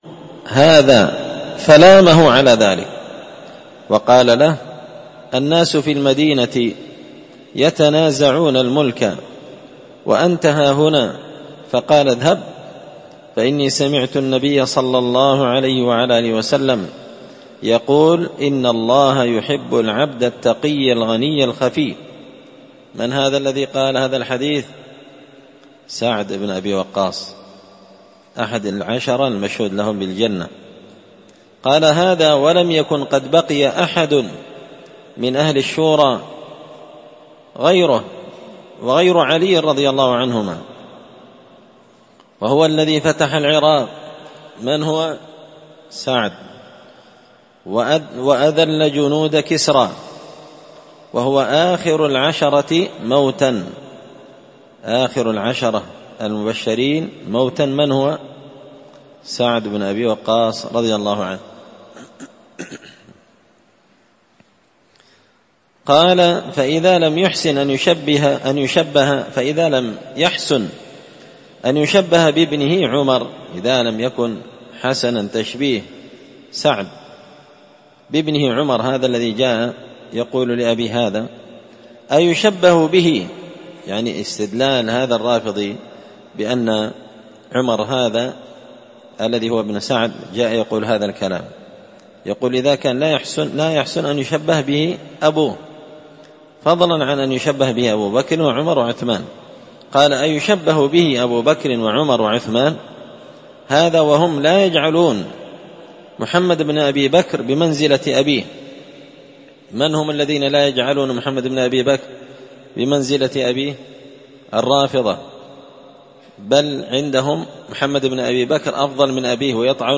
الجمعة 25 شعبان 1444 هــــ | الدروس، دروس الردود، مختصر منهاج السنة النبوية لشيخ الإسلام ابن تيمية | شارك بتعليقك | 7 المشاهدات